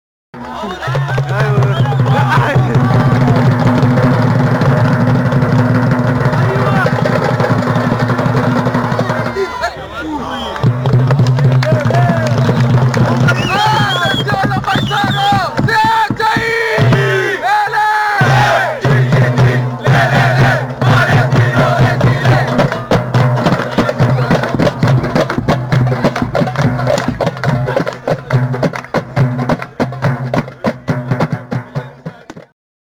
[ Pour écouter, cliquez ici ] «¡ATENCIÓN LOS BAISANOS!» CHANT DES BAISANOS CHANT DES SUPPORTERS DE PALESTINO